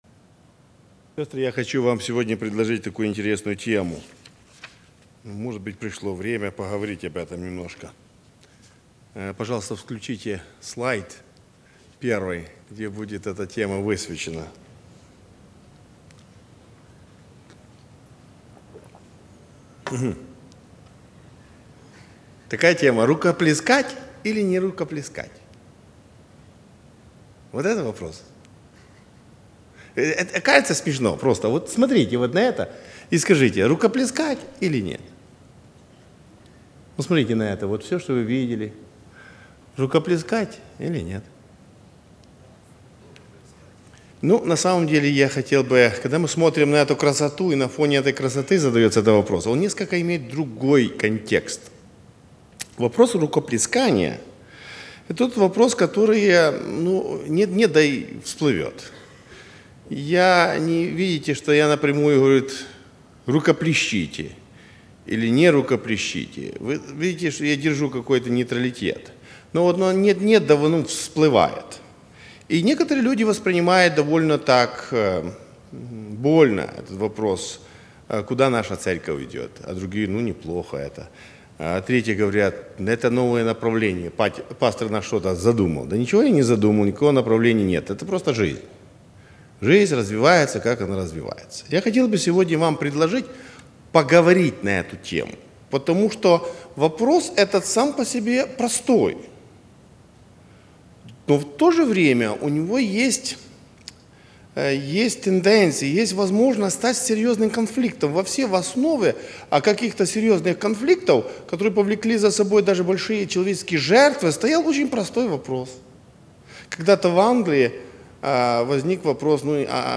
О внутренней красоте сердца - Аудио Проповедь